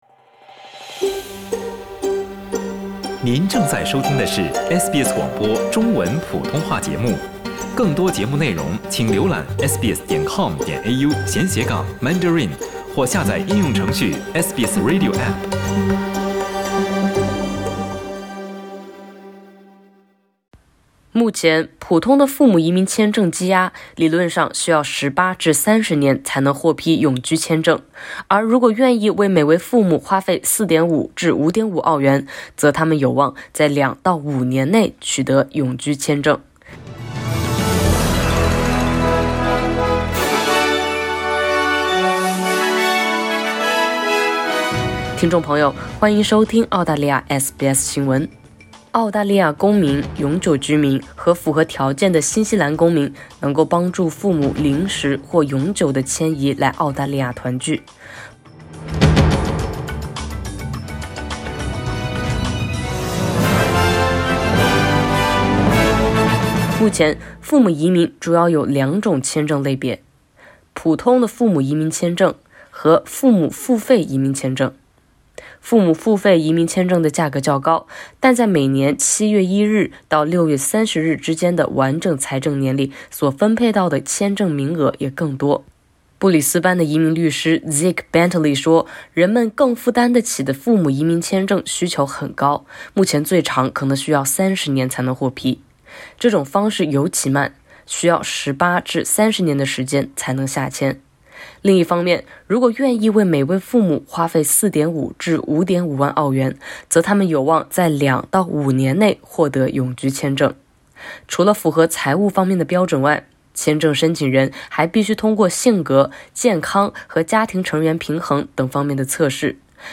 希望父母来澳团聚，有多种签证选择。其中一种方式可以让他们在两到五年内获得澳洲永居签证。（点击上图收听报道）